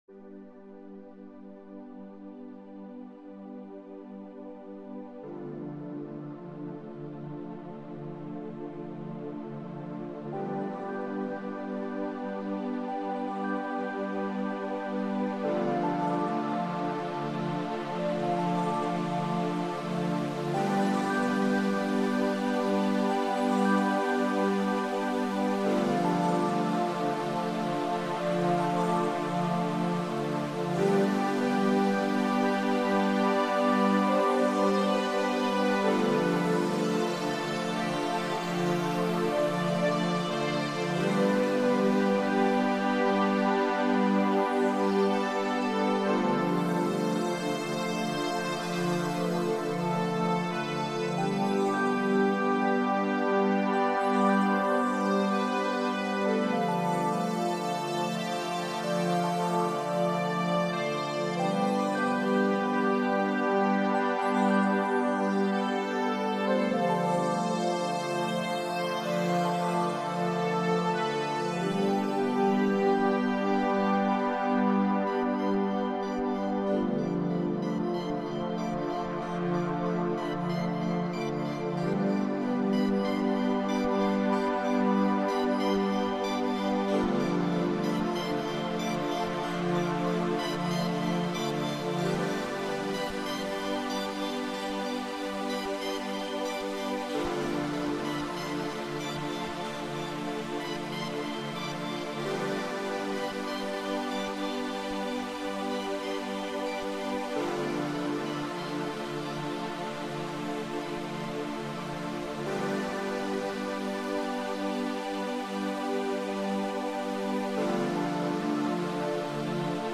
Well, take this, mate, your own chillout music!